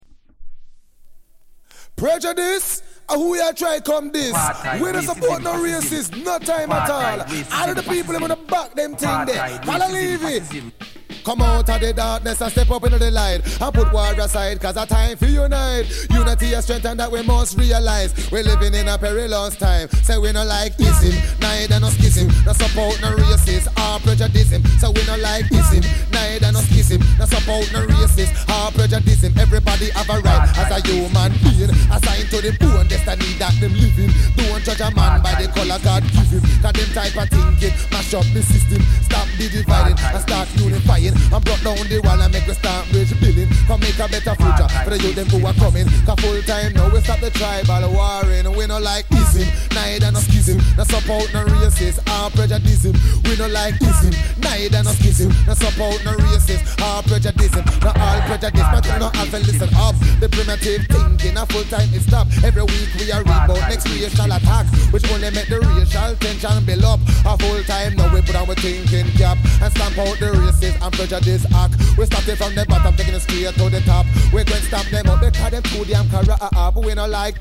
レゲエ